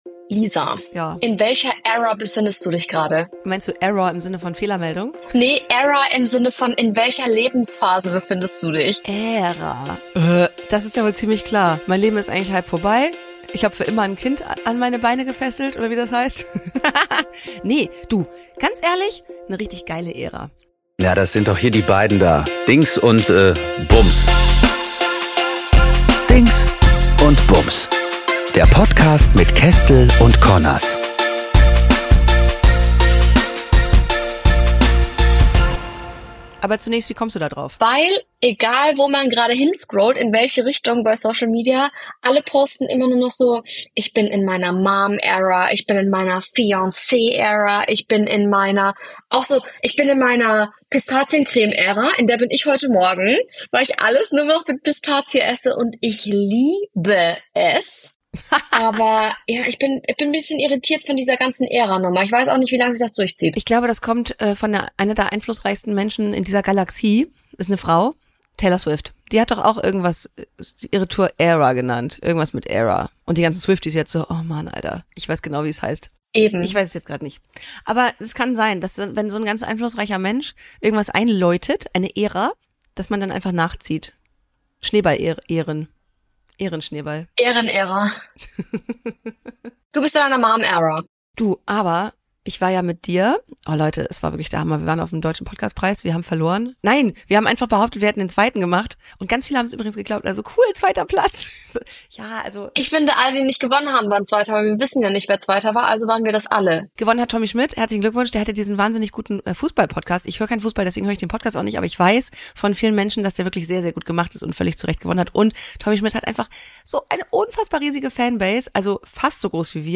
in dieser Folge seid ihr sogar wirklich live dabei!!!